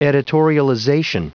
Prononciation du mot editorialization en anglais (fichier audio)
Prononciation du mot : editorialization